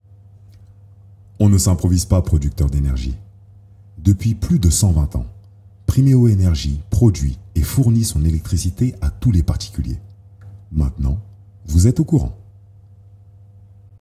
Selftape
30 - 55 ans - Basse Baryton-basse